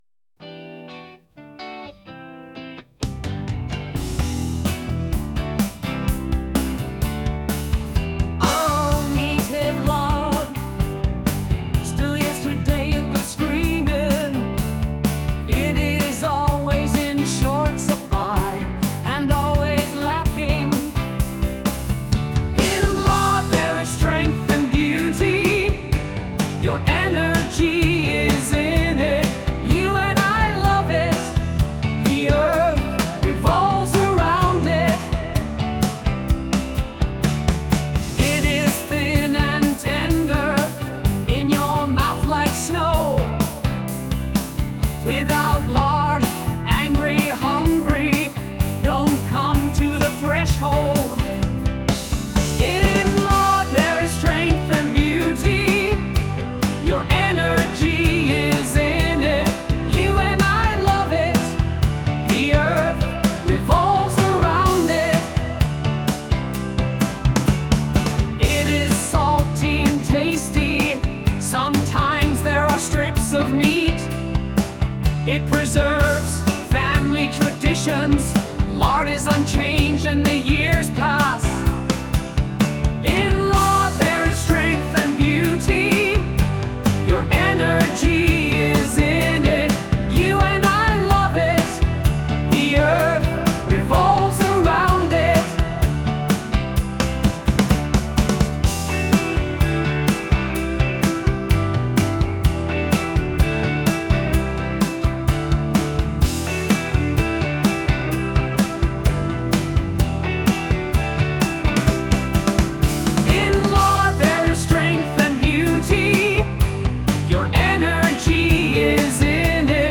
ТИП: Пісня
СТИЛЬОВІ ЖАНРИ: Гумористичний